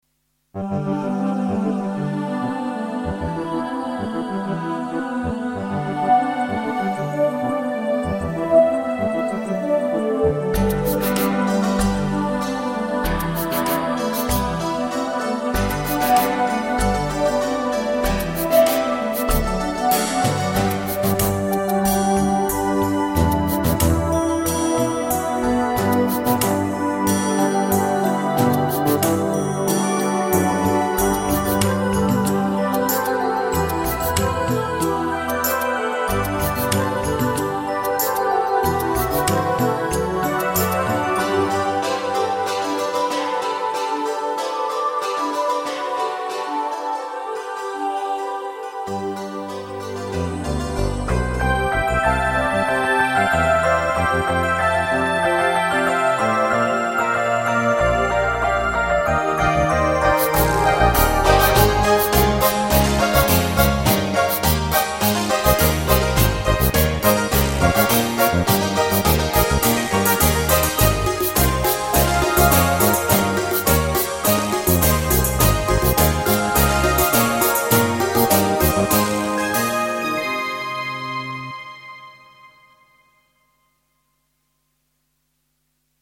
De folkloristische 'dulcimer' speelt hierin de hoofdrol temidden van elektronische koren en instrumenten.
De Sinterklaassfeer staat hierin centraal en ik heb er dan ook een rechtenvrij, Hollandse sinterklaasdeuntje in verwerkt.